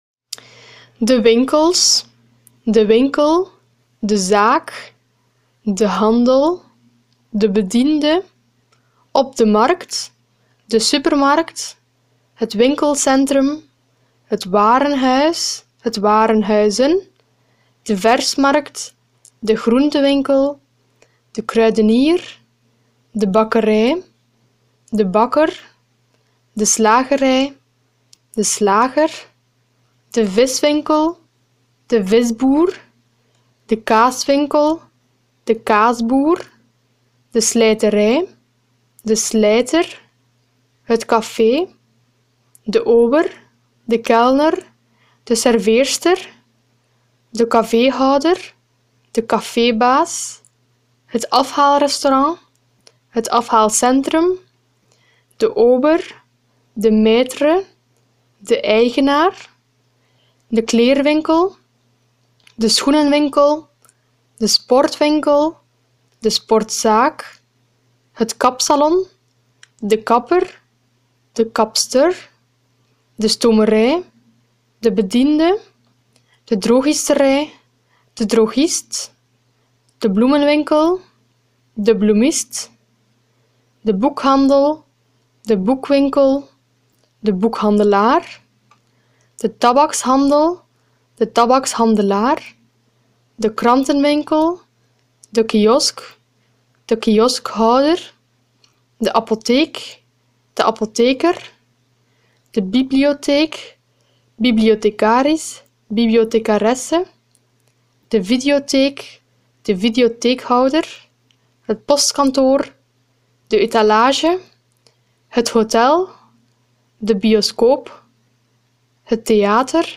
VL